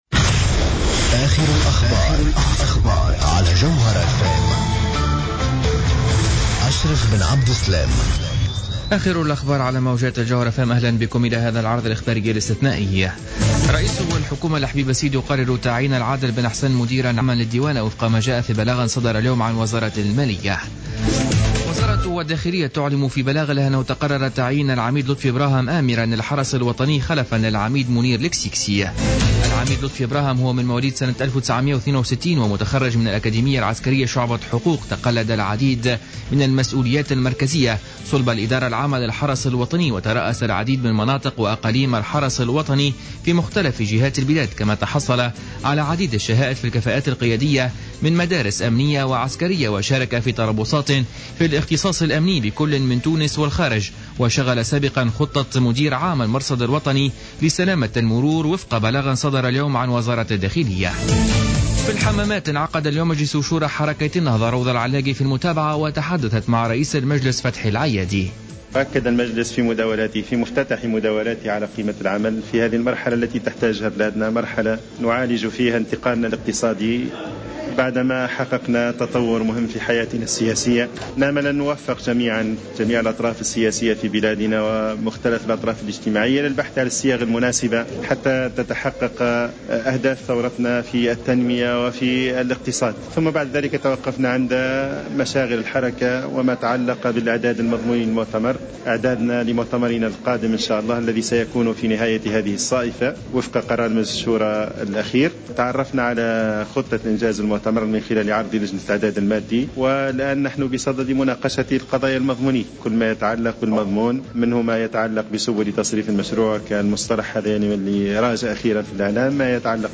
نشرة أخبار السابعة مساء ليوم السبت 02 ماي 2015